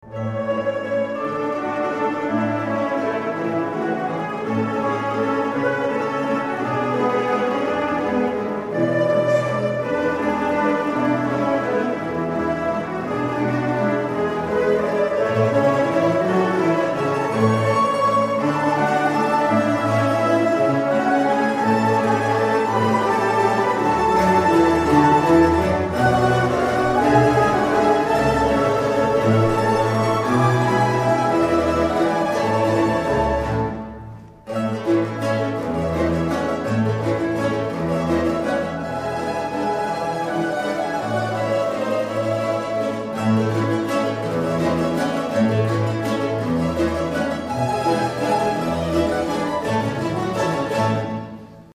駒ヶ根マンドリーノ　第２７回定期演奏会
会　場　　　　　駒ヶ根市文化会館　大ホール